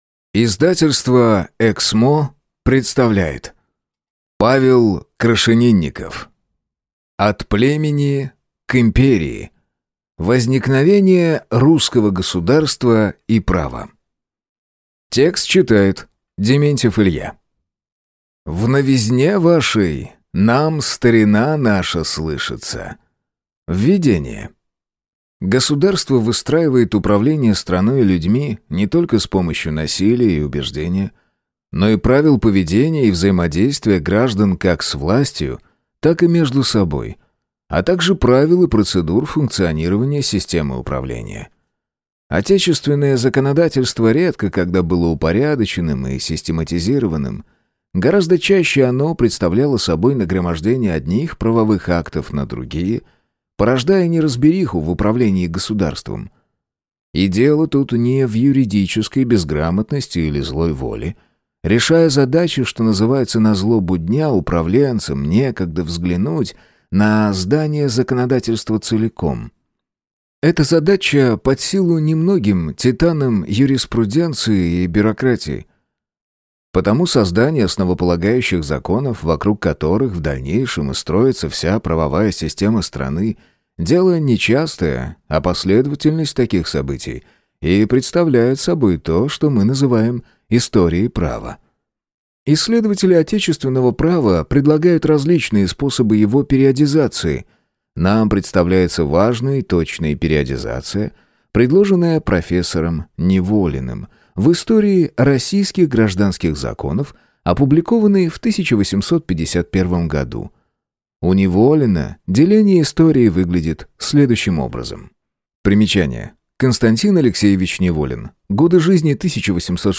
Аудиокнига От племени к империи. Возникновение русского государства и права | Библиотека аудиокниг